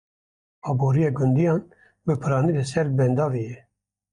Pronounced as (IPA)
/pɪɾɑːˈniː/